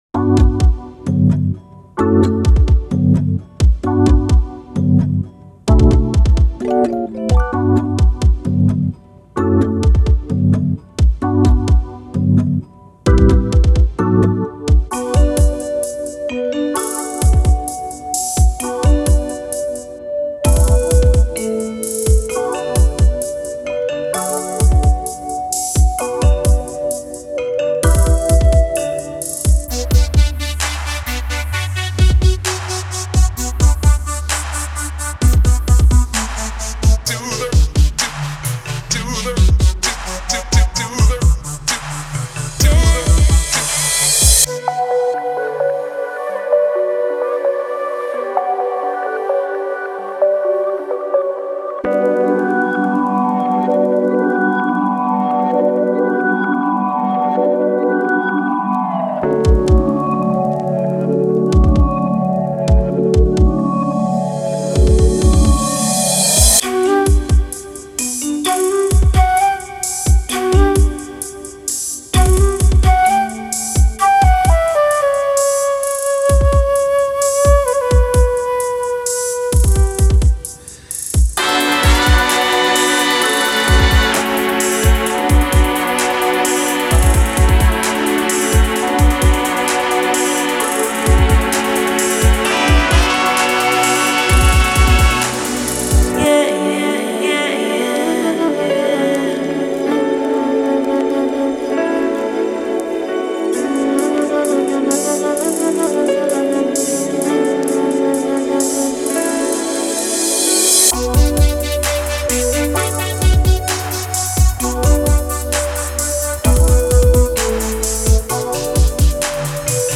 / Electronic